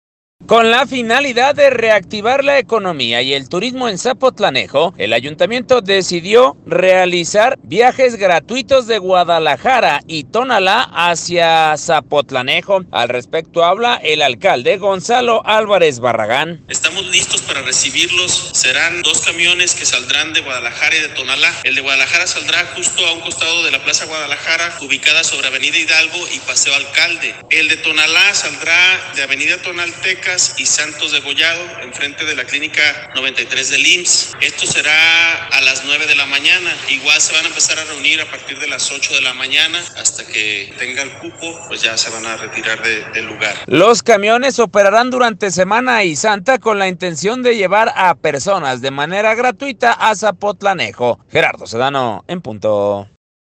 Con la finalidad de reactivar la economía y el turismo en Zapotlanejo, el ayuntamiento decidió realizar viajes gratuitos de Guadalajara y Tonalá hacia Zapotlanejo, al respecto habla, el alcalde Gonzalo Álvarez Barragán: